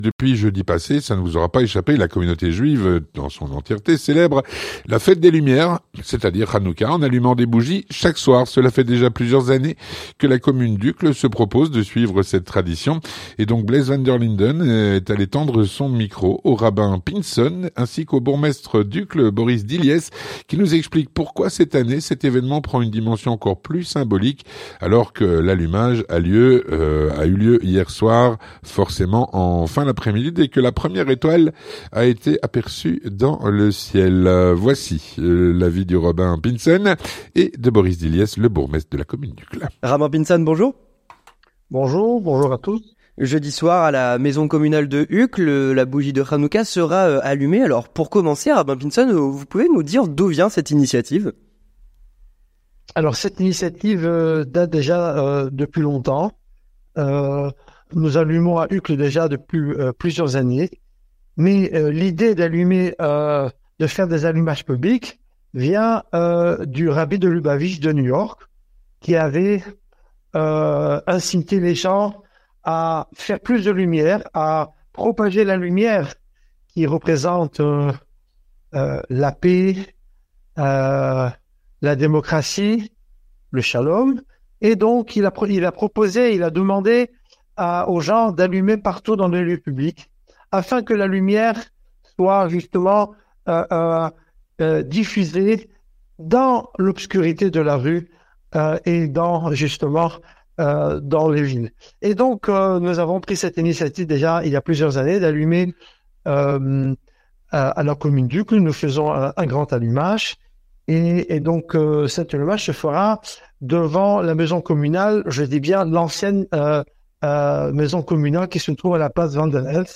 L'entretien du 18H - Allumage des bougies de Hanoucca ce mercredi à Uccle.